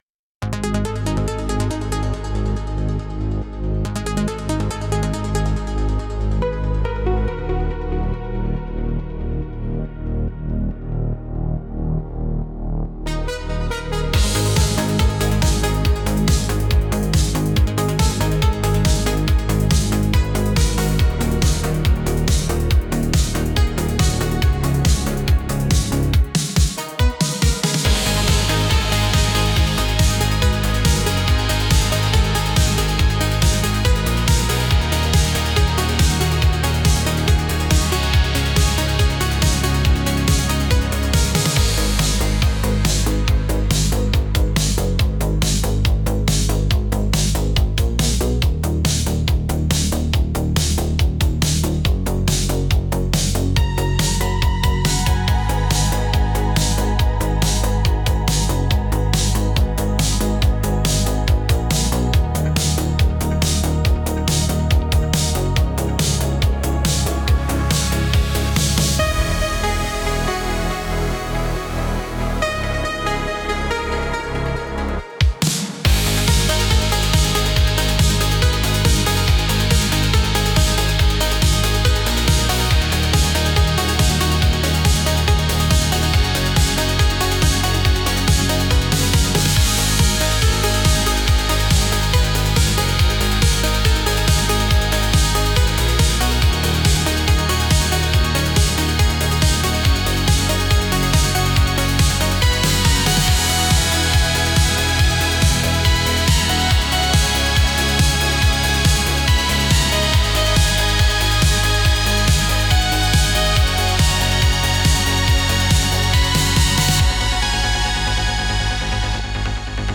Instrumental - Voltage Veins 2.59